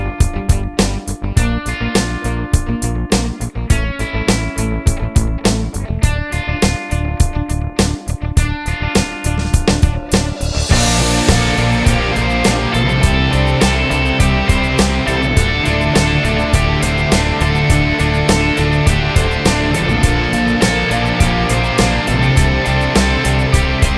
Minus Main Guitar Pop (2000s) 4:05 Buy £1.50